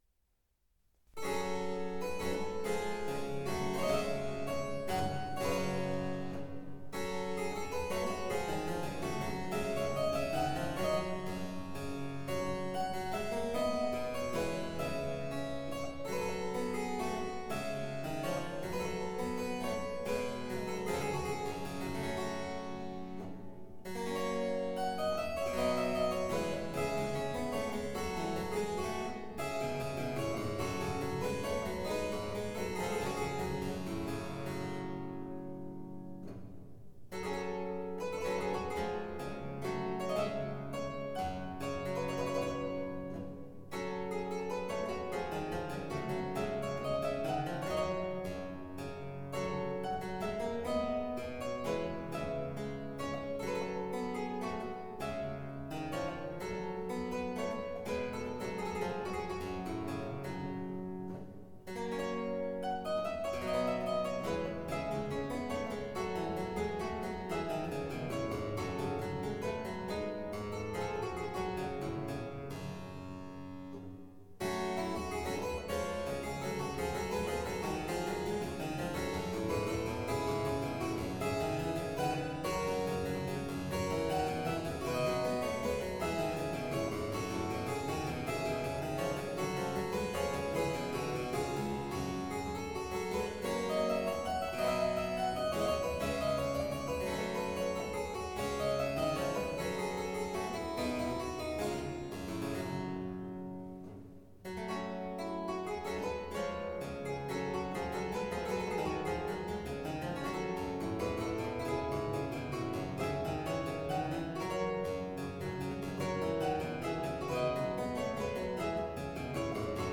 Robin (Rotkelchen) – Cembalo-Solo